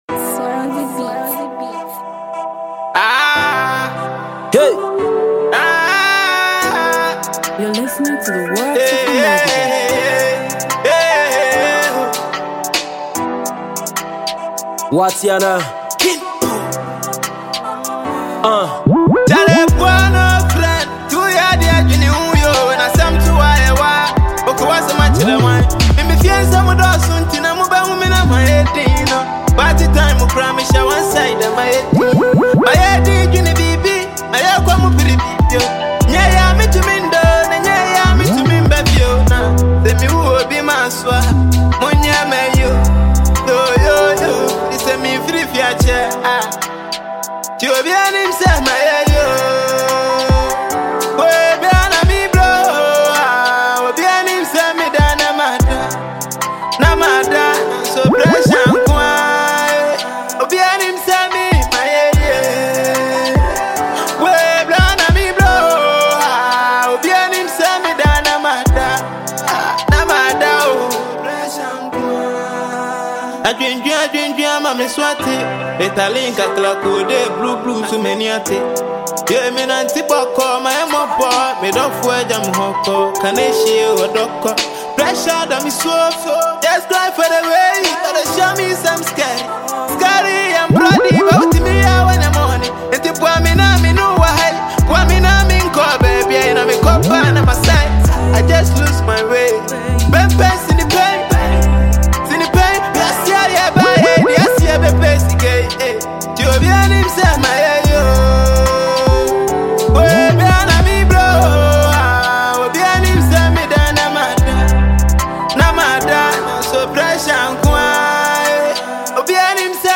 Ghanaian rapper
Award-winning rapper